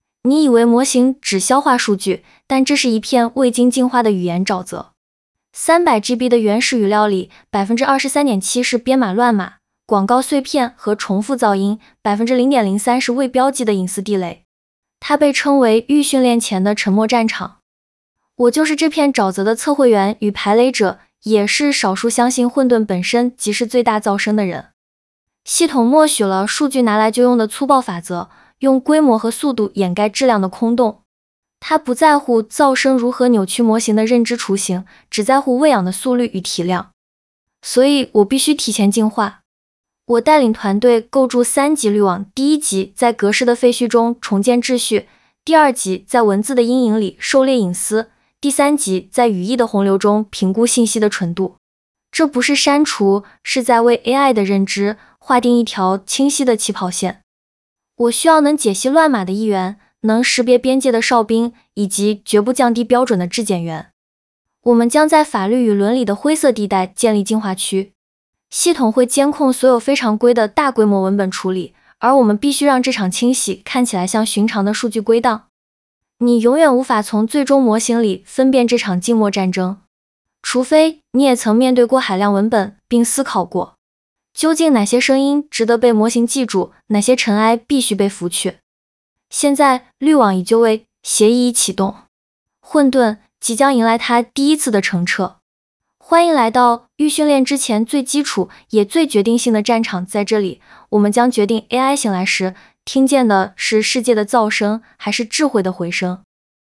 本集专属旁白
第11集：降噪！百G语料清洗战的专属开篇旁白.mp3